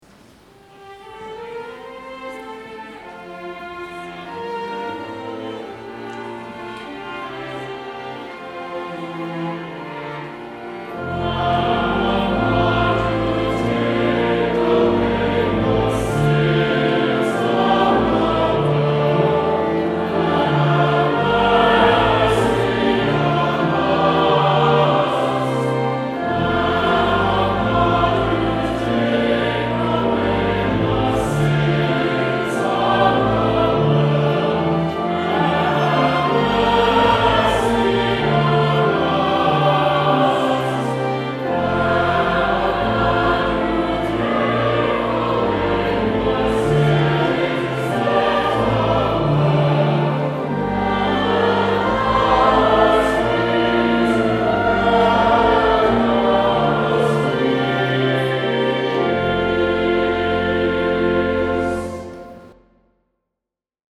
Lakewood Cluster Choirs of Saint Clement, Saint James and Saint Luke Sang this Song
2023 Easter Vigil